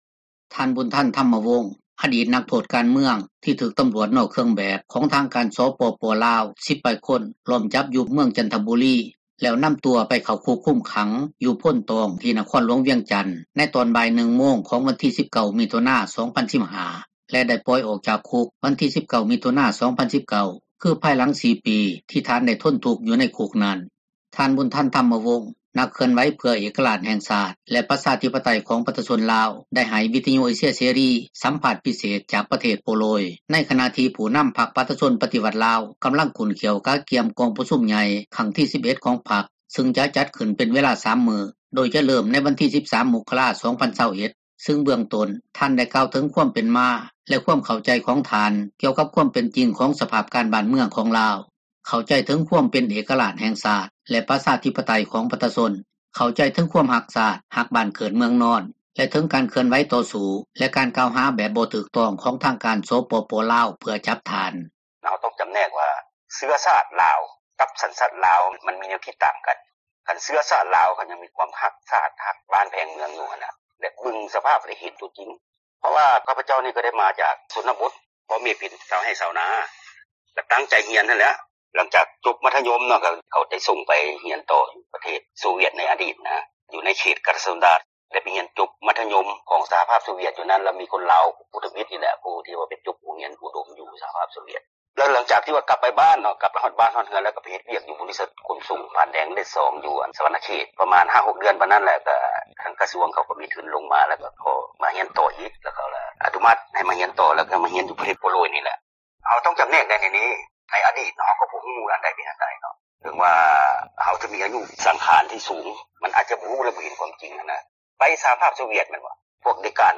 ສໍາພາດ ພິເສດ ຈາກ ປະເທດ ໂປໂລຍ